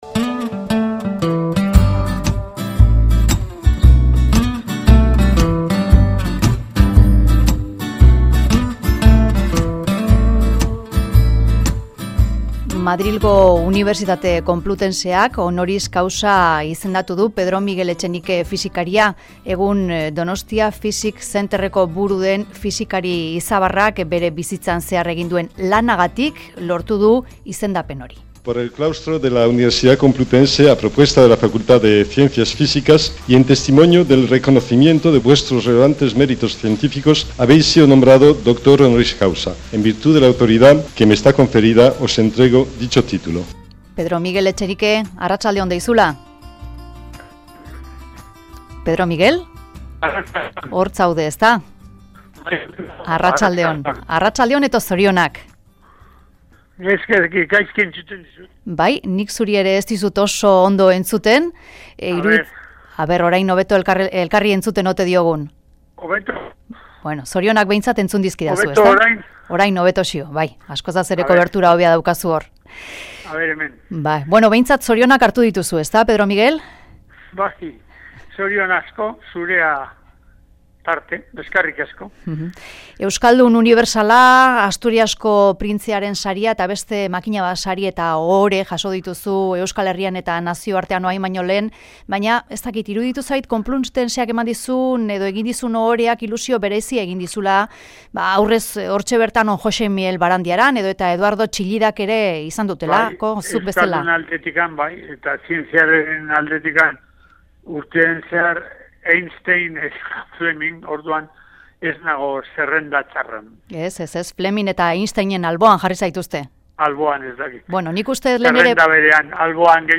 Mezularia, Pedro Miguel Etxenike Doctor Honoris Causa izendatu du Madrilgo Complutense Unibertsitateak. Oso eskertuta hitz egin digu.